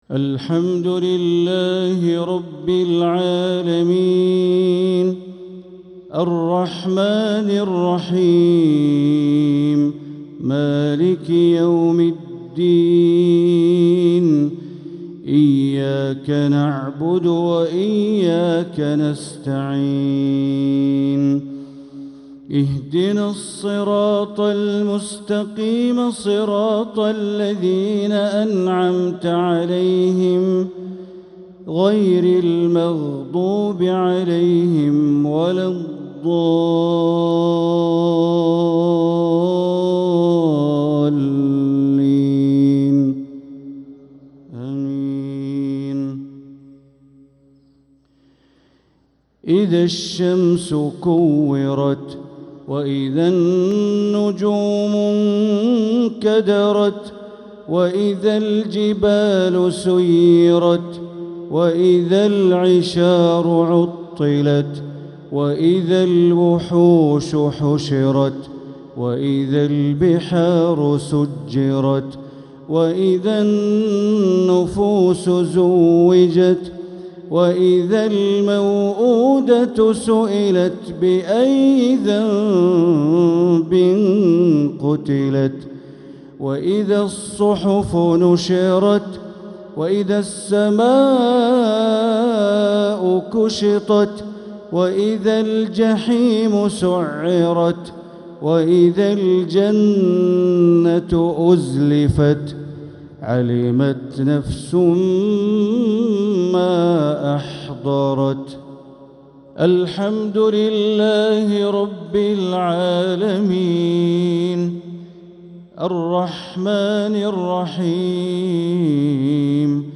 Maghrib prayer from Surat at-Takwir 4-2-2025 > 1446 > Prayers - Bandar Baleela Recitations